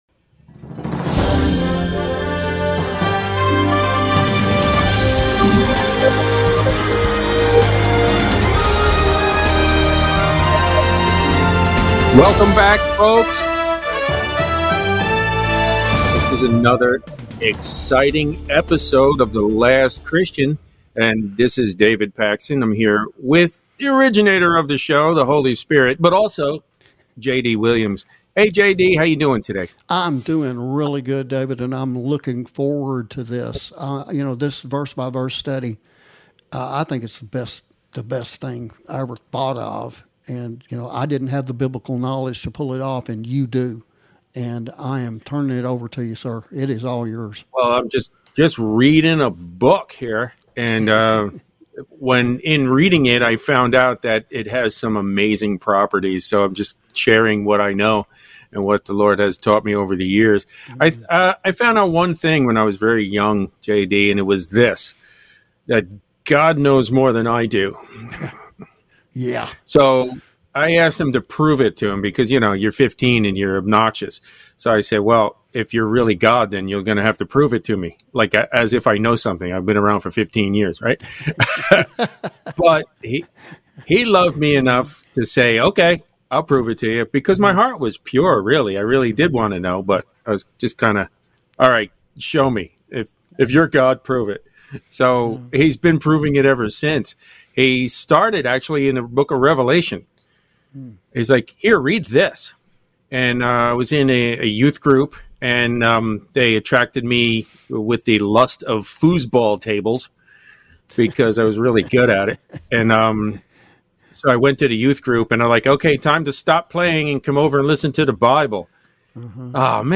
Verse by Verse Bible Study for November 30th 2023